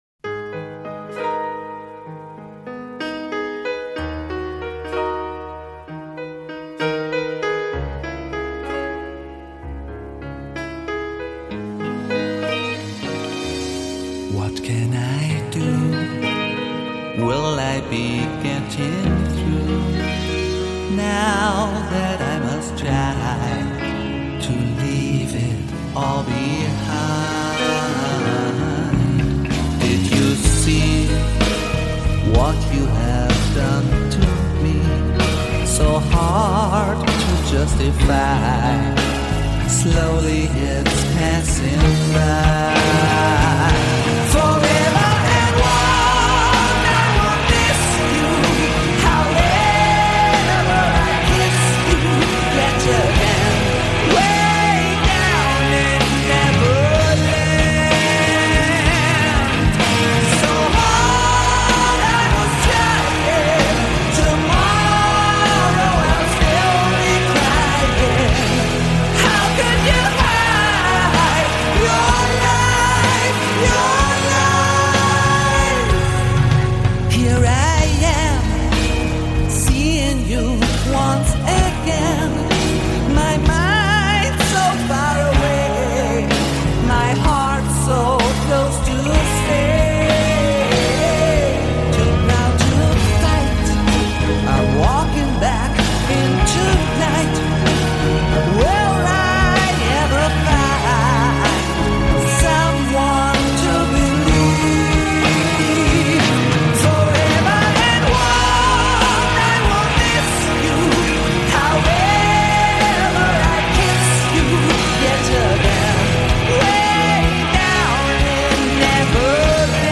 Жанр: Rock
СТАРЫЕ ЗАРУБЕЖНЫЕ БАЛЛАДЫ